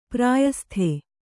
♪ prāyasthe